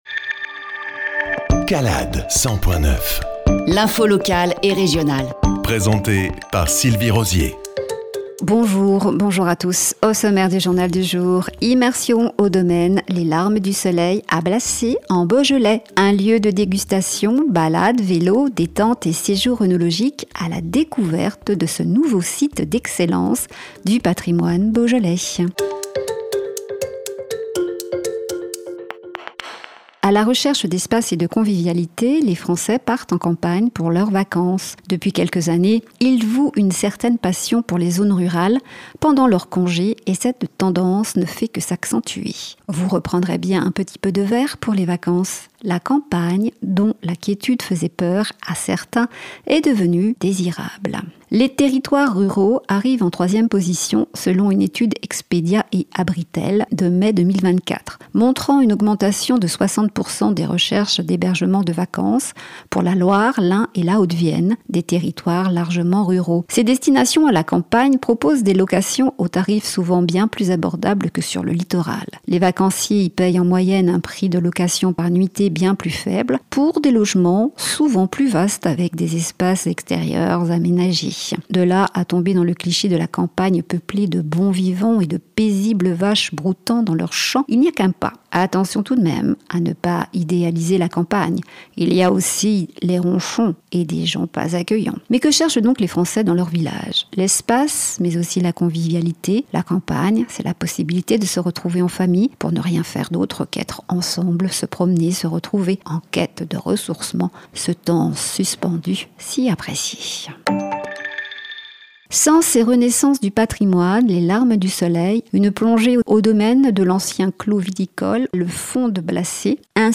JOURNAL – 250425